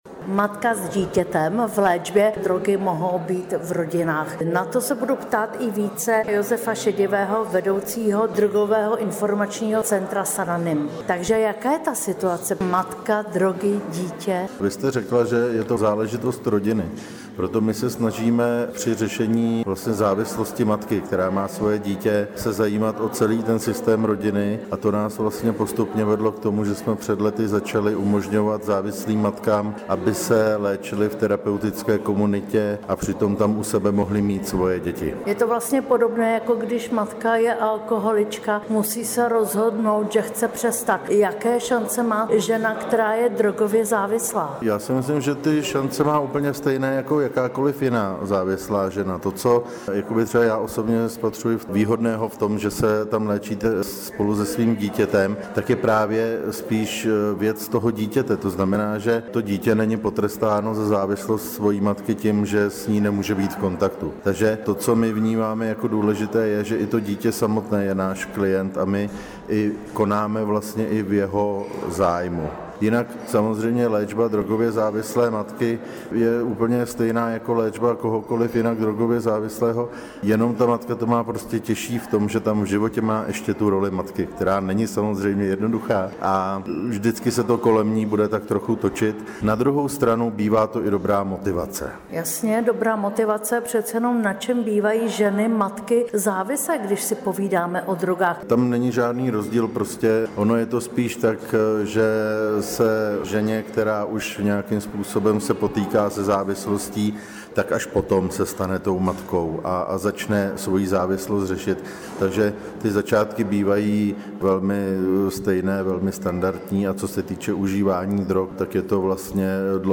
AUDIO rozhovor